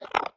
1.21.5 / assets / minecraft / sounds / mob / strider / idle4.ogg